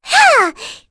Luna-Vox_Attack3.wav